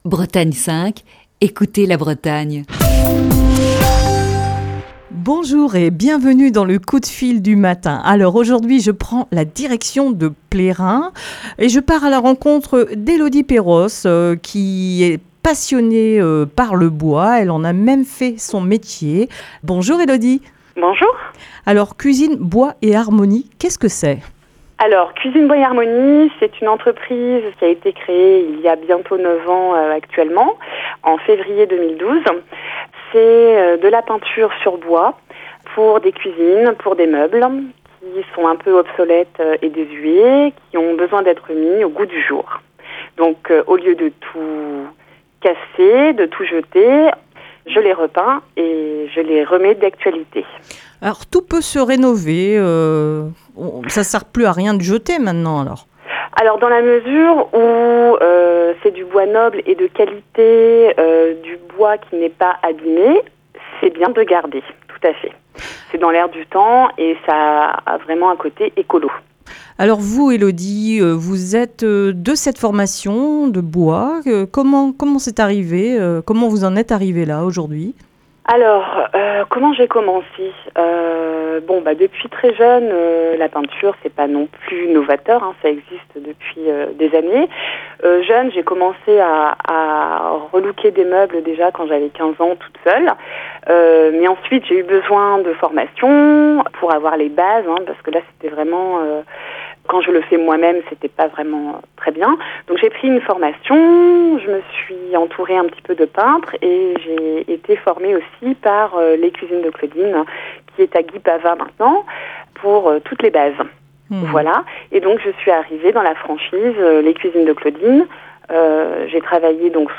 Aujourd'hui dans le coup de fil du matin